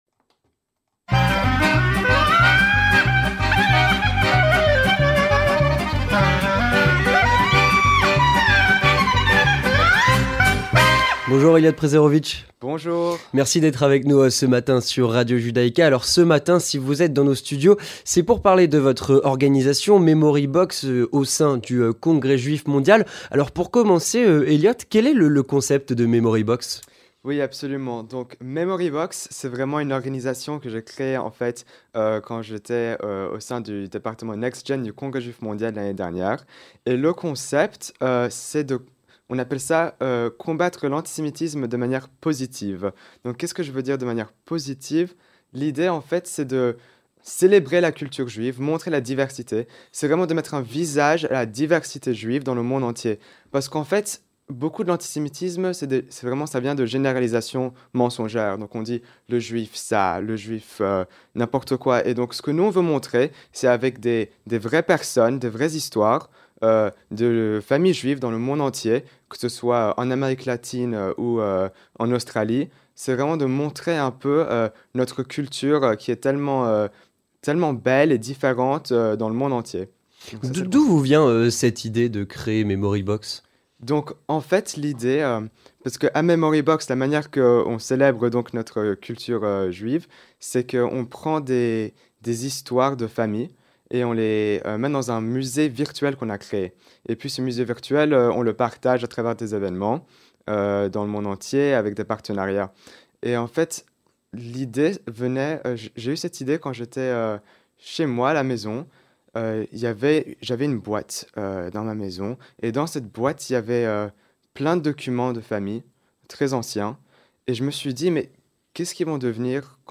Interview Communautaire - L'organisation Memory Box